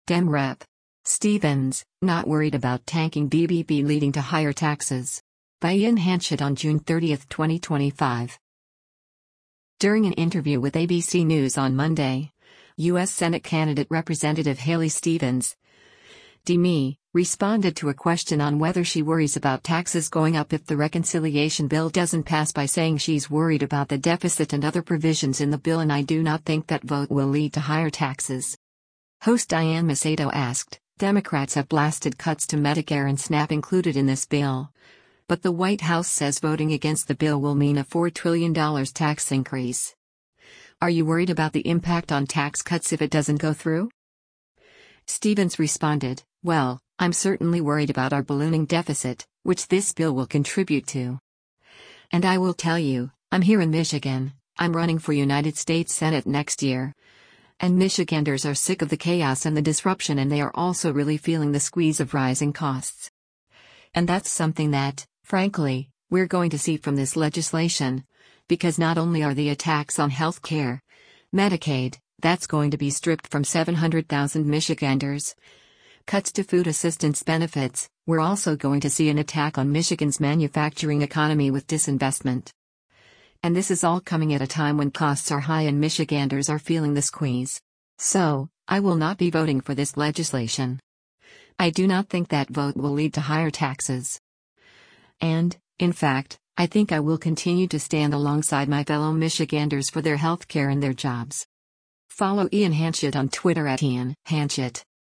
During an interview with ABC News on Monday, U.S. Senate candidate Rep. Haley Stevens (D-MI) responded to a question on whether she worries about taxes going up if the reconciliation bill doesn’t pass by saying she’s worried about the deficit and other provisions in the bill and “I do not think that vote will lead to higher taxes.”